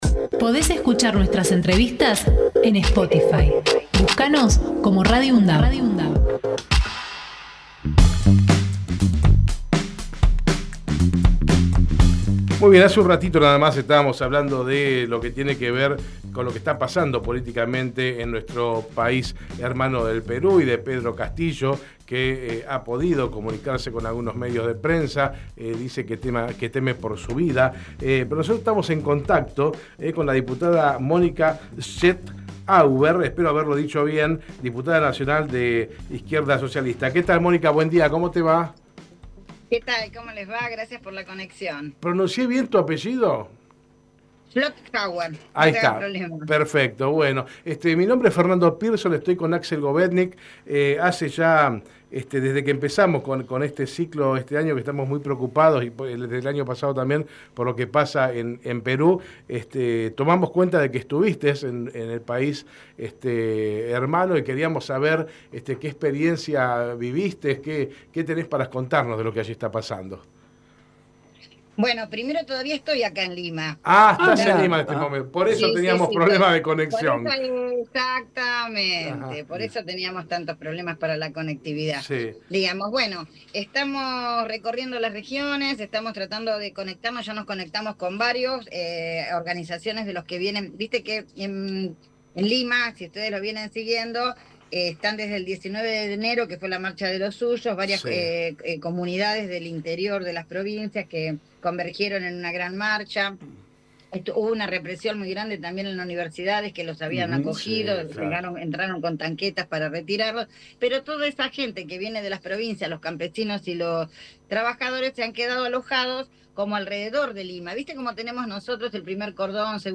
Mónica Schlotthauer en Hacemos PyE Texto de la nota: Compartimos la entrevista realizada en Hacemos PyE a Mónica Schlotthauer, diputada nacional por la Izquierda Socialista. Conversamos sobre su visita a Perú y analizamos la situación social que atraviesan en el país en el marco de las movilizaciones que se dieron en contra del gobierno de Dina Boluarte.